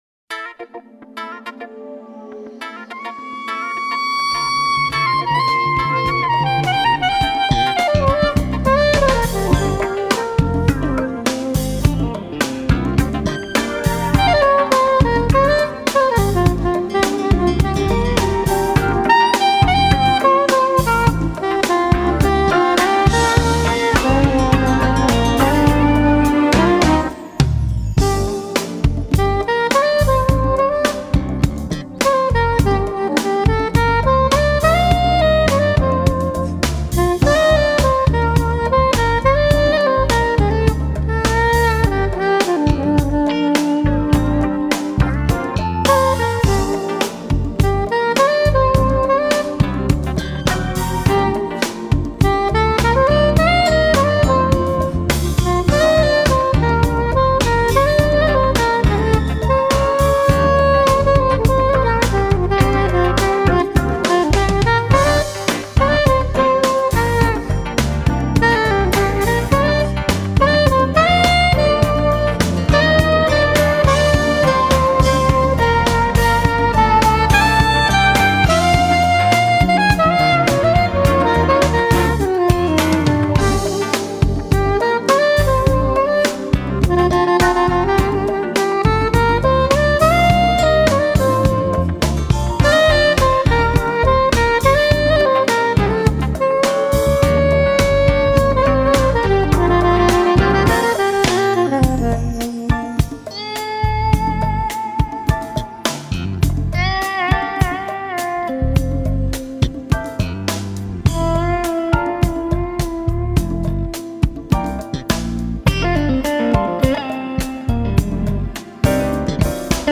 Funk Jazz